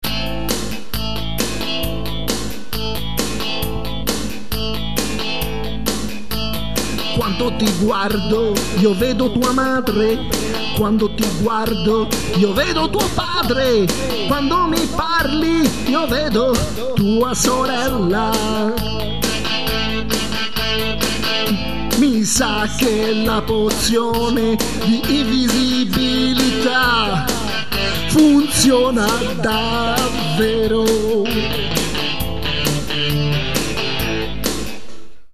Un delirio improvvisato per pochi secondi (per fortuna)